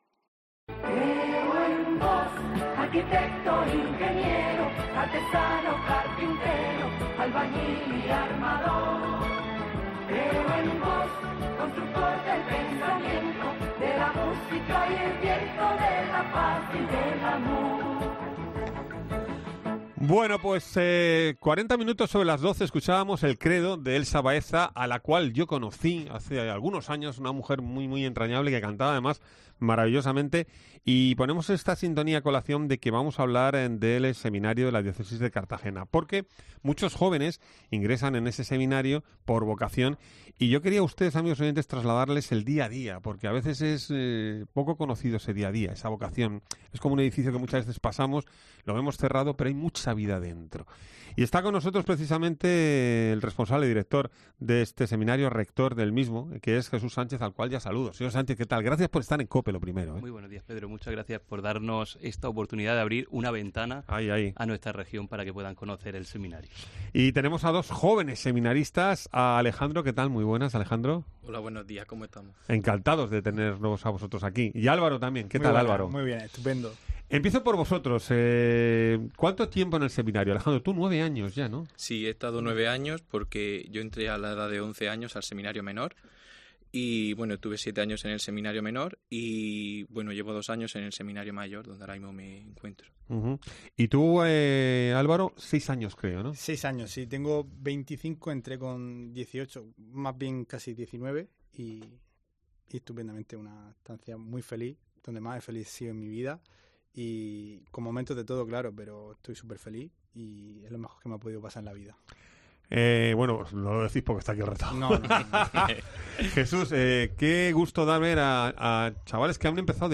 La entrevista completa puedes oírla en COPE MURCIA.